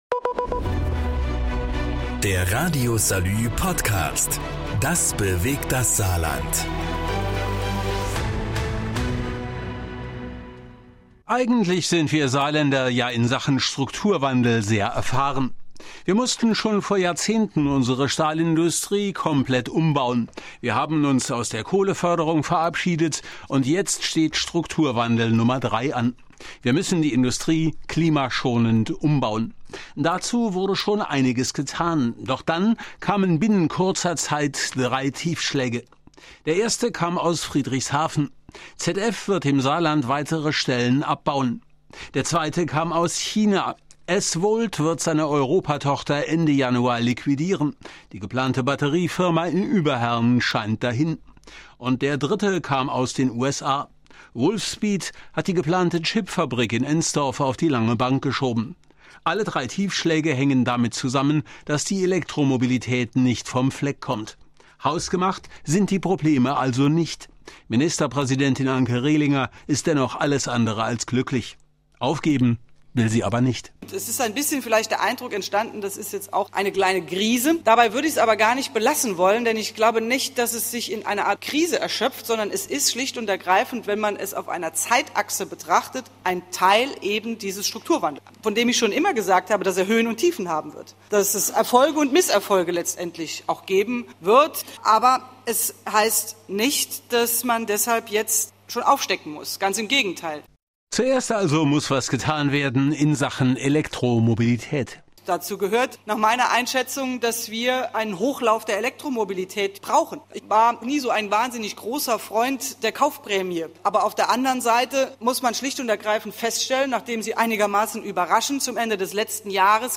Und er hat einige interessante Stimmen eingefangen Mehr